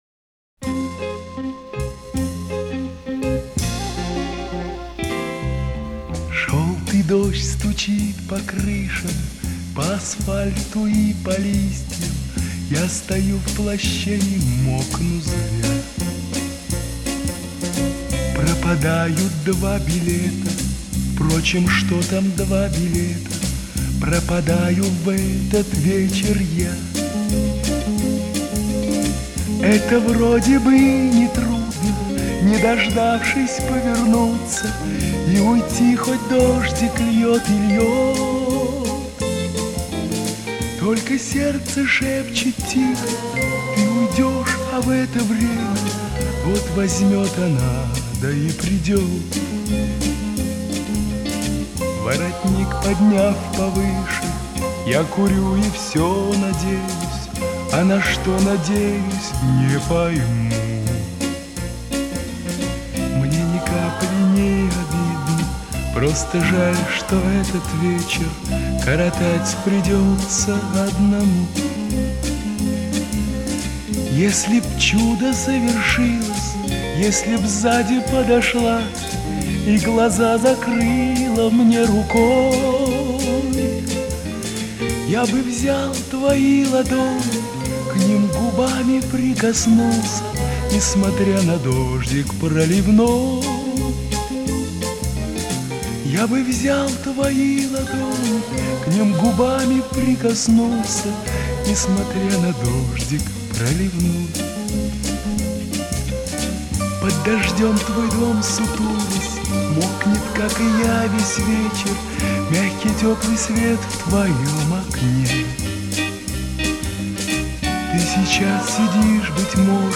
Ищу песню на этот инструментал.